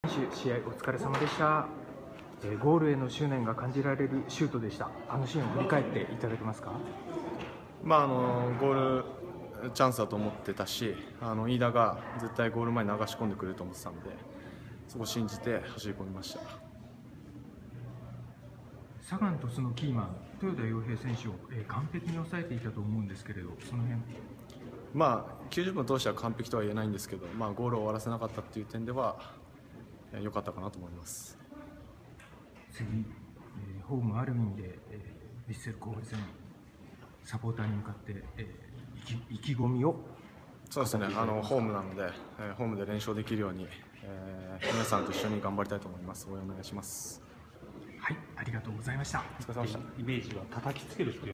インタビュー